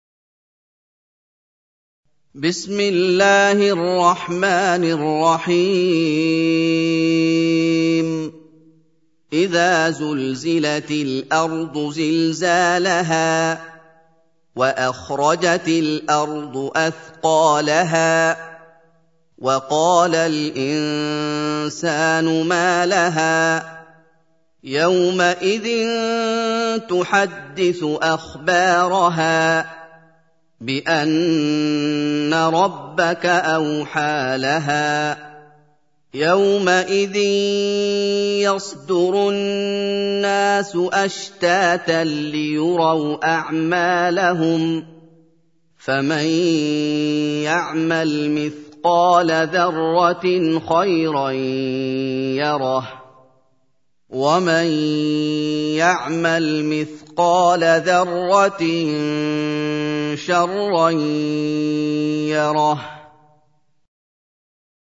سورة الزلزلة | القارئ محمد أيوب